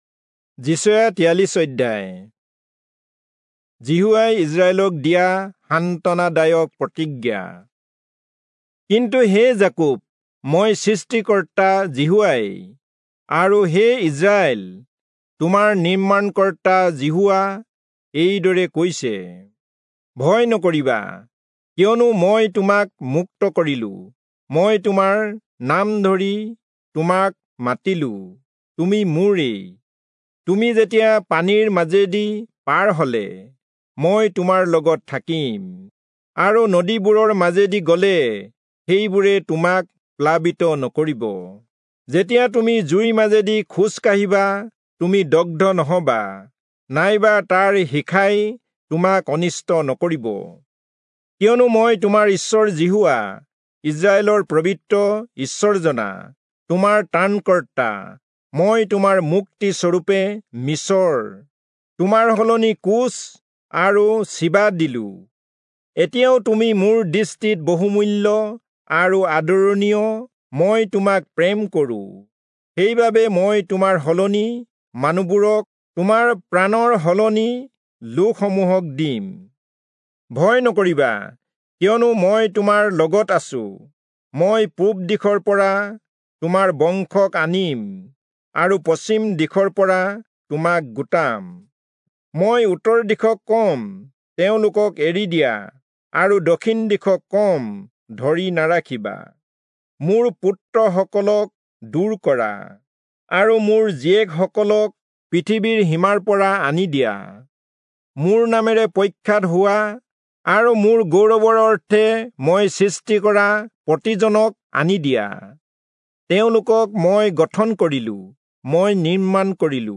Assamese Audio Bible - Isaiah 28 in Gntwhrp bible version